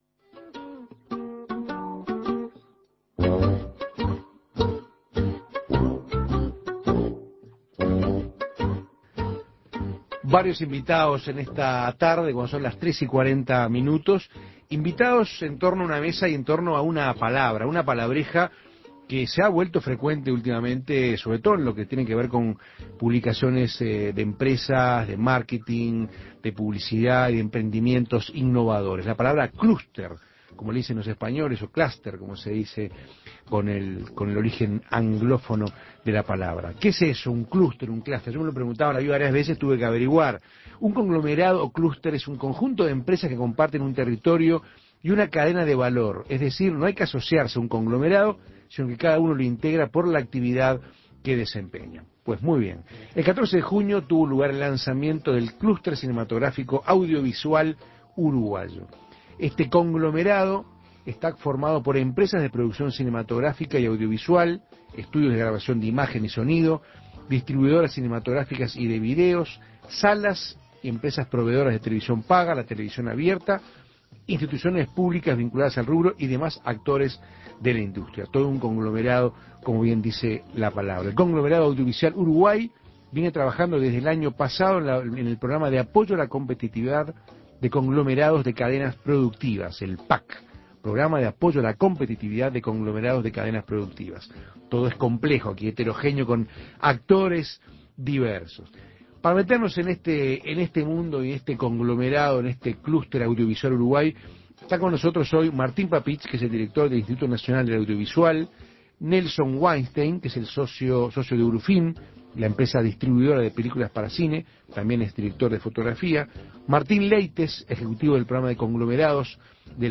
Escuche la entrevista con estos expertos en el tema